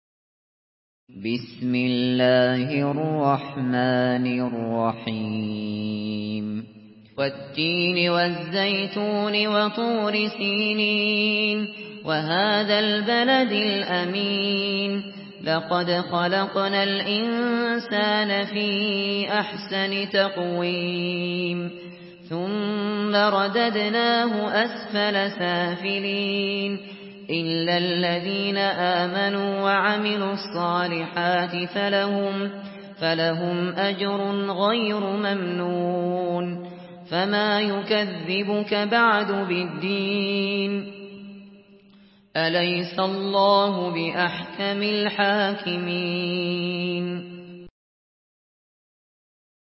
Surah At-Tin MP3 in the Voice of Abu Bakr Al Shatri in Hafs Narration
Murattal Hafs An Asim